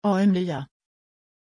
Aussprache von Amélya
pronunciation-amélya-sv.mp3